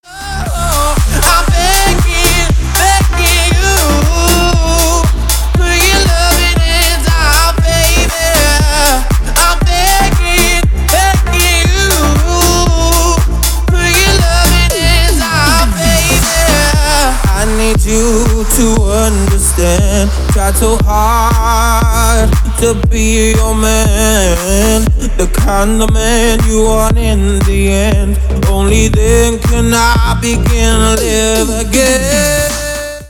• Качество: 320, Stereo
мужской голос
deep house
Electronic
EDM